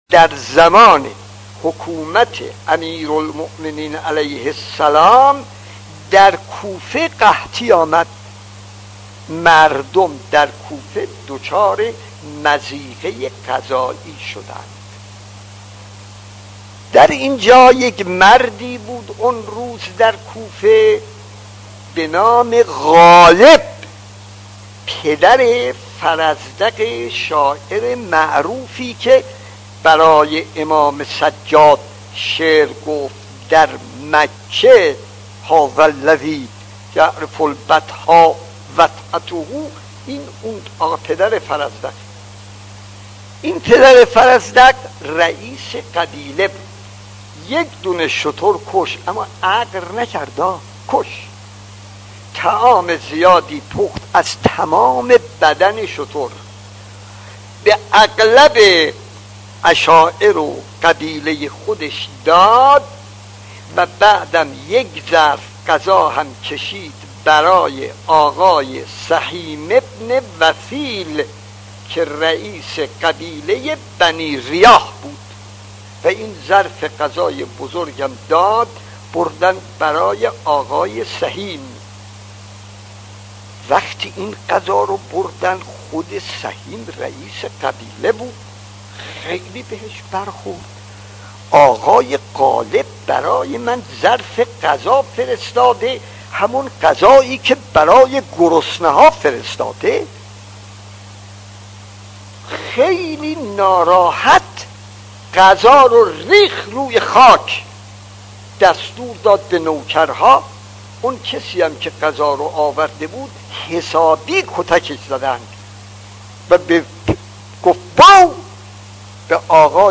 داستان 7 : قحطی در زمان حکومت امام علی خطیب: استاد فلسفی مدت زمان: 00:08:05